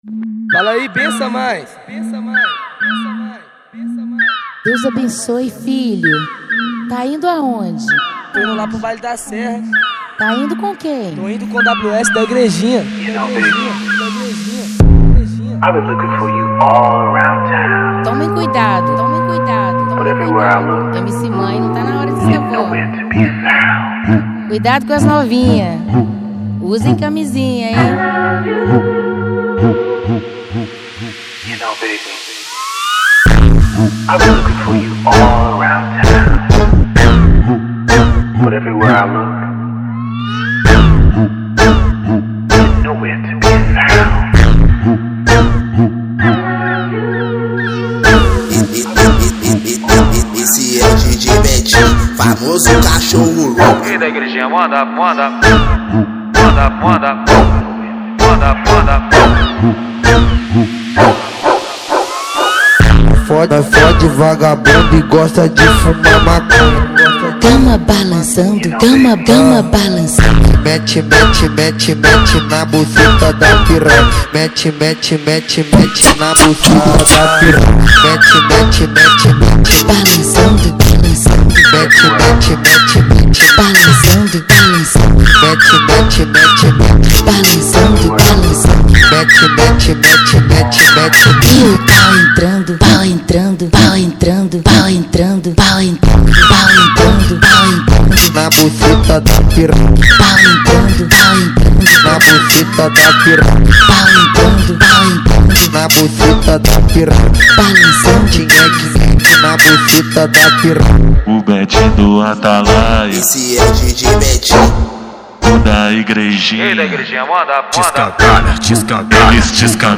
2024-10-13 23:16:26 Gênero: Funk Views